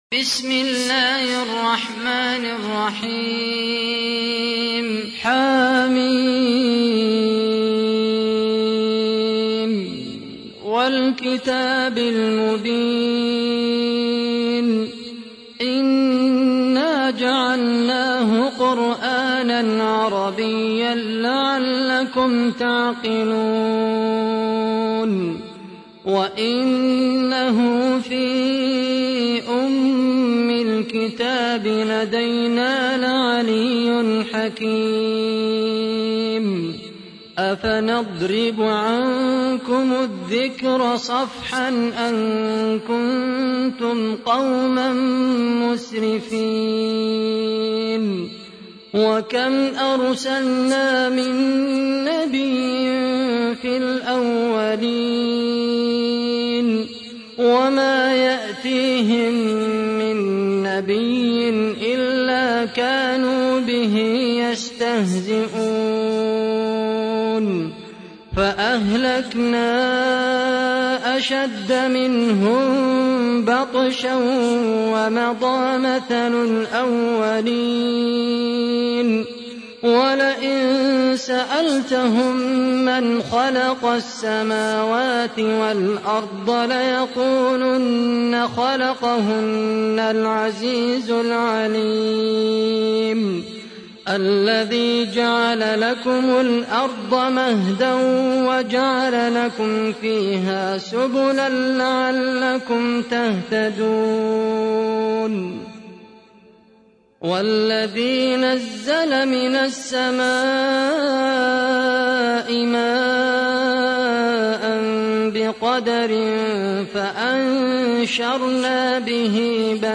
تحميل : 43. سورة الزخرف / القارئ خالد القحطاني / القرآن الكريم / موقع يا حسين